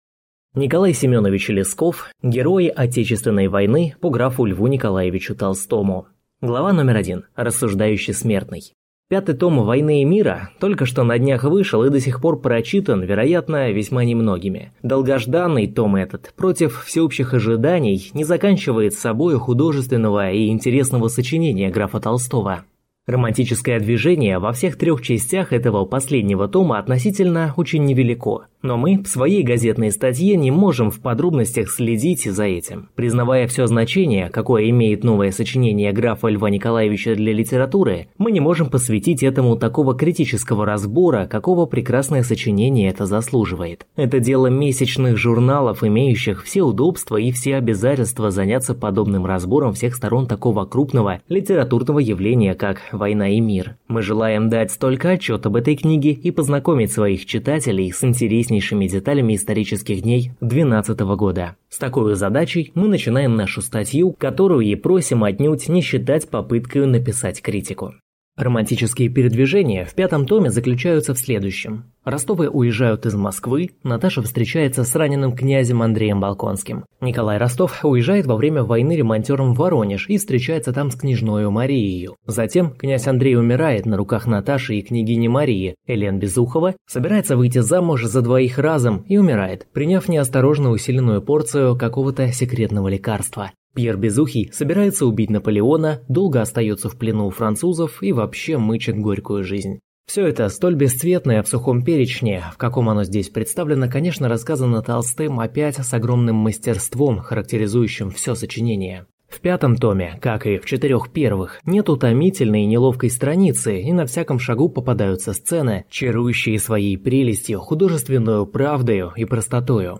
Аудиокнига Герои Отечественной войны по гр. Л. Н. Толстому | Библиотека аудиокниг